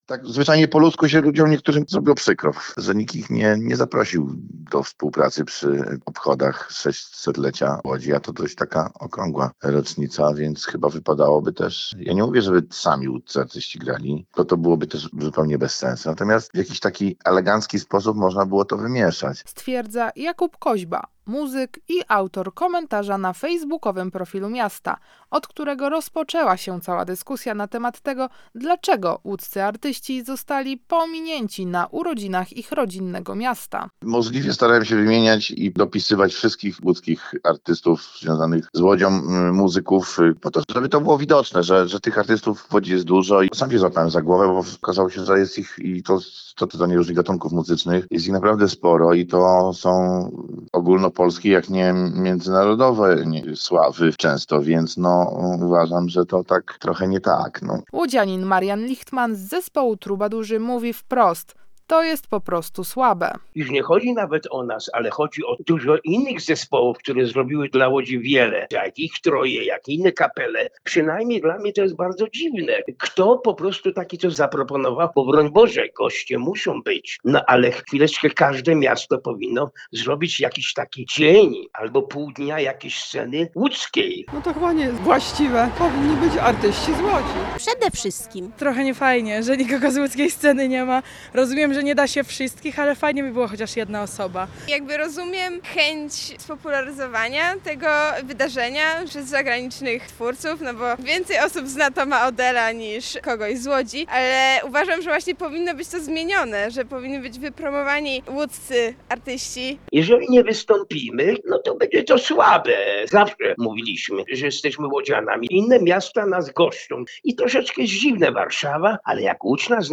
Łodzianin Marian Lichtman członek zespołu trubadurzy mówi wprost to jest po prostu słabe.